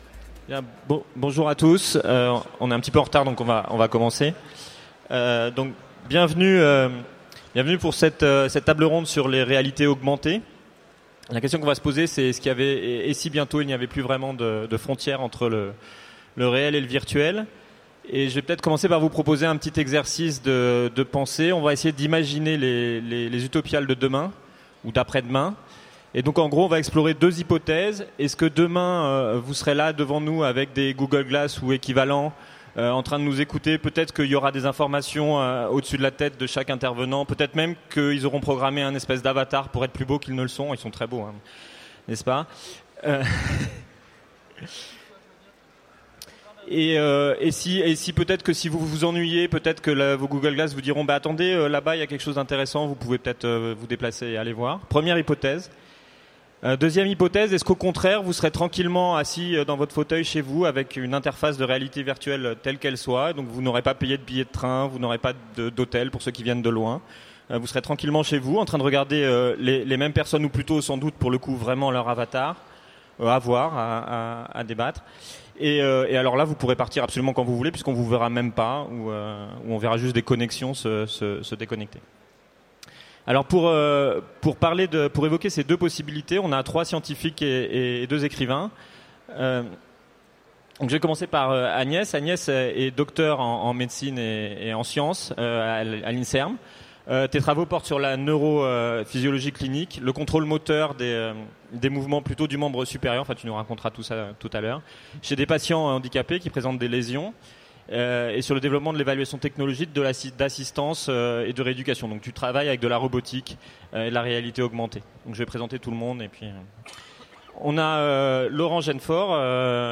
Utopiales 2015 : Conférence Les réalités augmentées